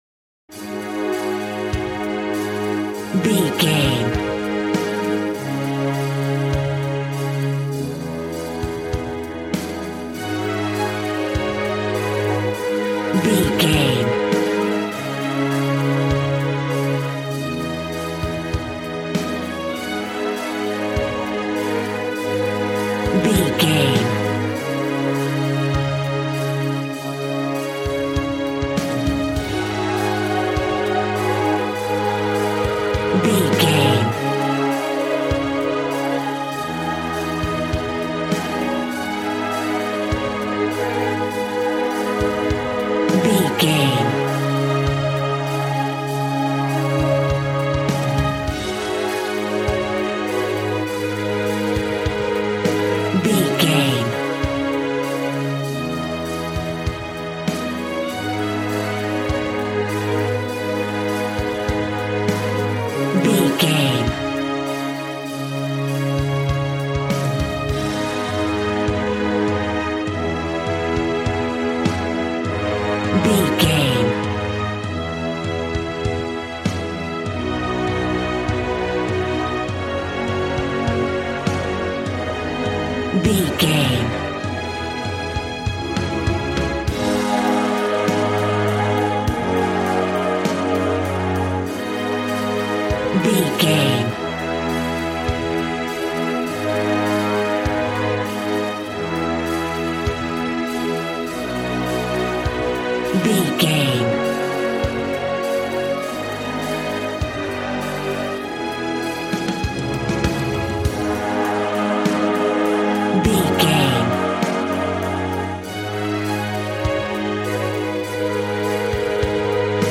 Aeolian/Minor
A♭
dramatic
strings
violin
brass